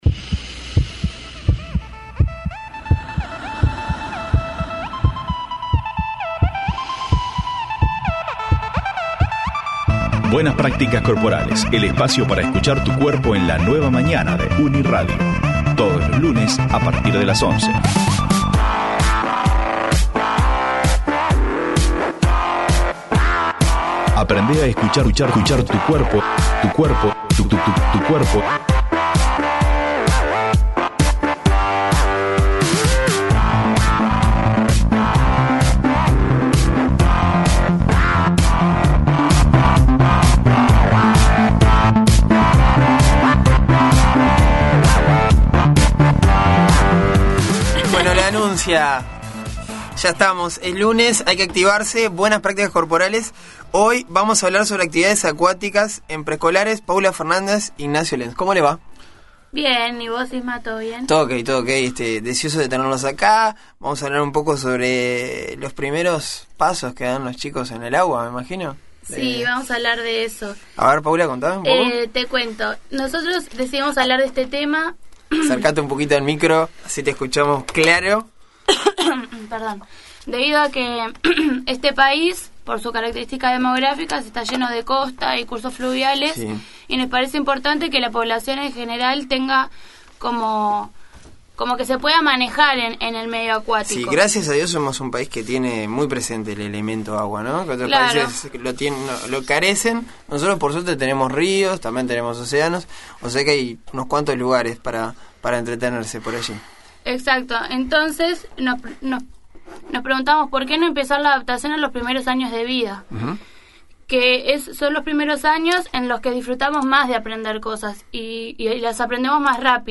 En la columna de Buenas Prácticas Corporales de La Mañana de Uni Radio, en el marco del Proyecto de Extensión del Instituto Superior de Educación Física (ISEF).